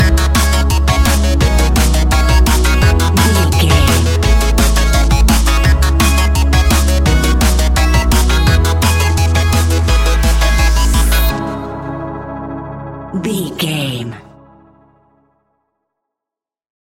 Ionian/Major
C♯
electronic
techno
trance
synths
synthwave
instrumentals